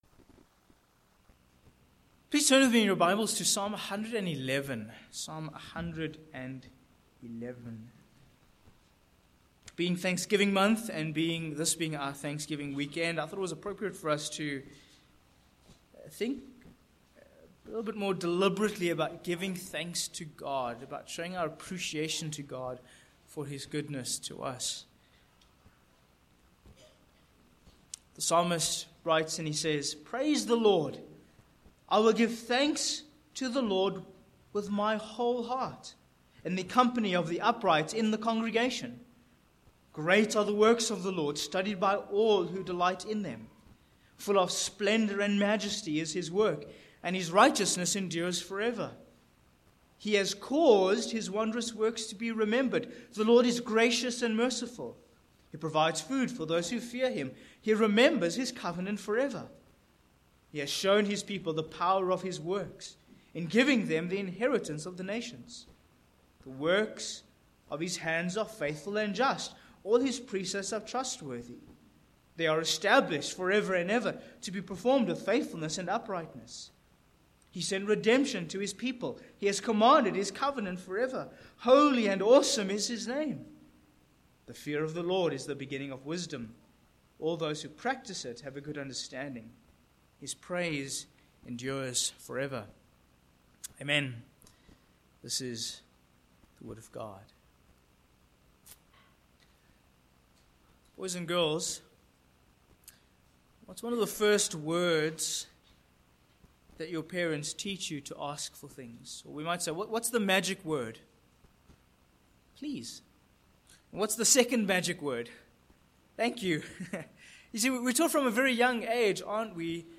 2023 A Song of Covenant Celebration Preacher
Psalm 111:1-10 Service Type: Morning Passage